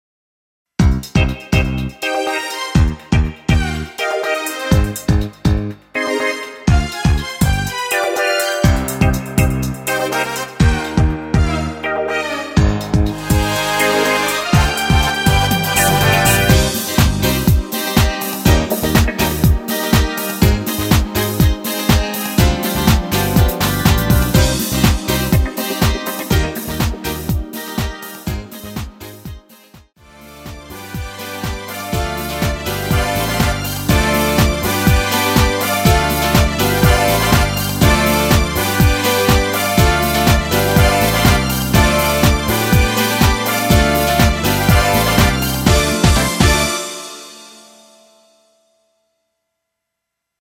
전주가 길어서 앞부분의 드럼 솔로 삭제와 엔딩의 페이드 아웃 부분은 엔딩을 만들었습니다.(미리듣기 참조)
Cm
앞부분30초, 뒷부분30초씩 편집해서 올려 드리고 있습니다.